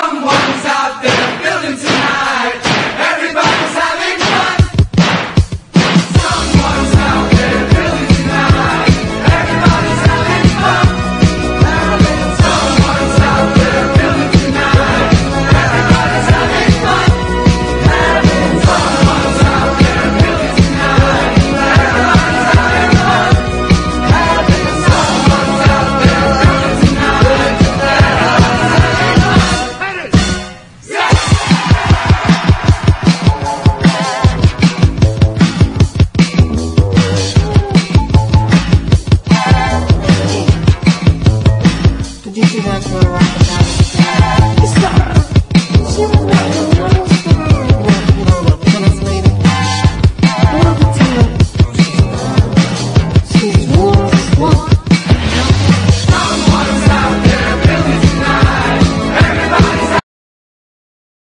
EASY LISTENING / OTHER / SKIFFLE / OLDIES
アメリカのフォーク/カントリーがトラッド・ジャズと結びついてUKで生まれたダンス音楽＝スキッフル！
ジャイヴやロカビリー、スウィングなどと同列で踊れてしまうハッピーな音楽なのです！